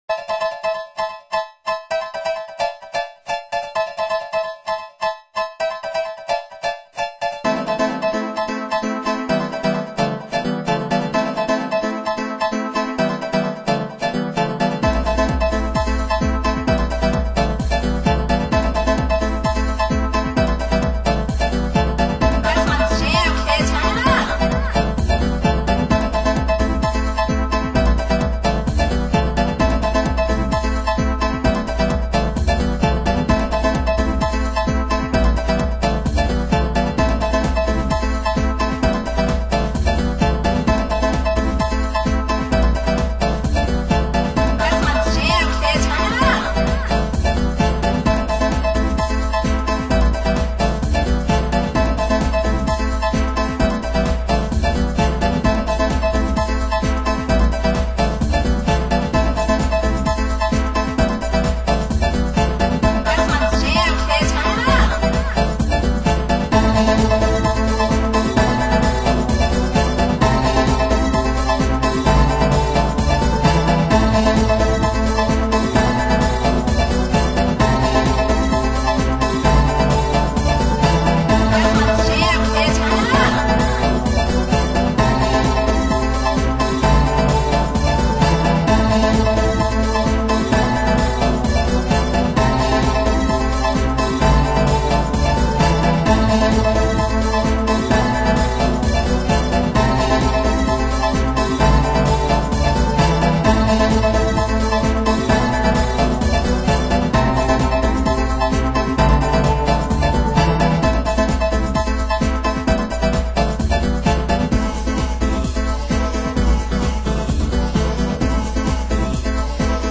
The scene - a dance club, any dance club.
Using ACID music creation software, and being very bored at the time, I turned to trying my hand at music.
In any case, some info of note: the voice you hear altered is mine. I am repeating some lines of a couple of my psaiku's (you'll find a place to buy the book elsewhere on this site).